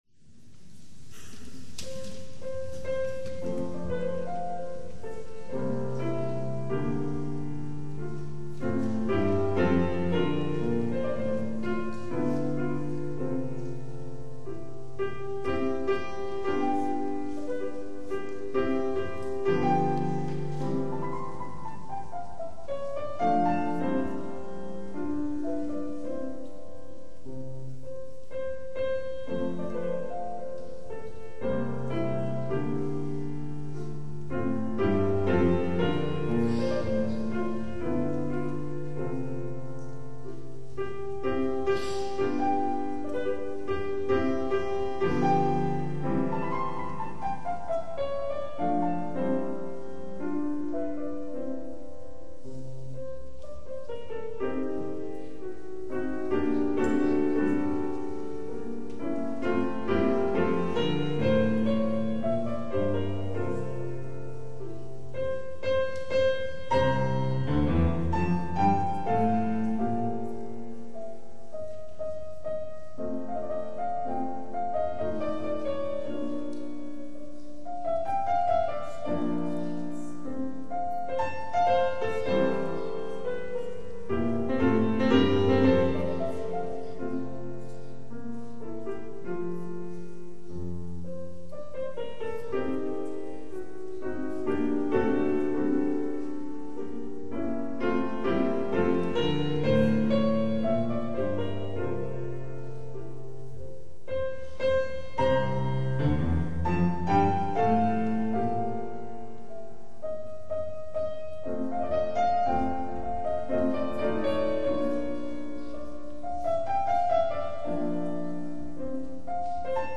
KV 330 Piano Sonata No. 10 in C major - Andante cantabile   [stream]